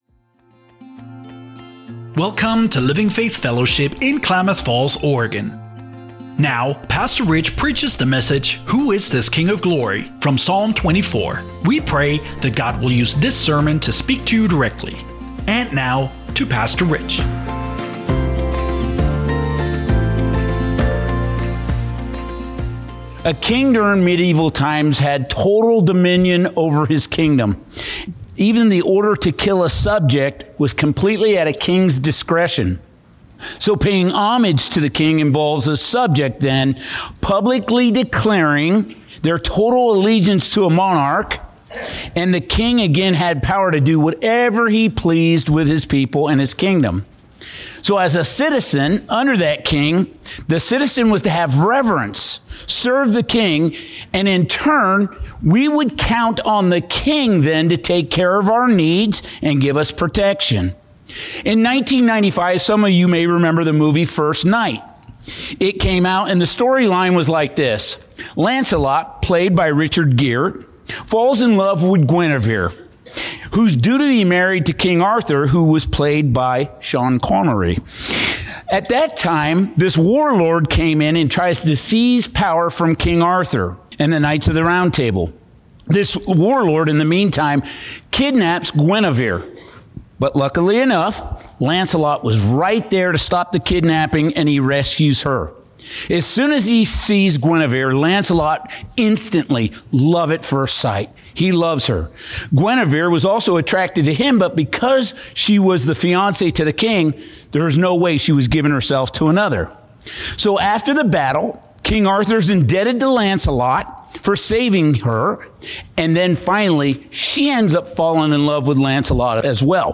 43:15 Download WATCH LISTEN SERMON SERMON NOTES Listen on Podcast Apple Podcasts Spotify Amazon Music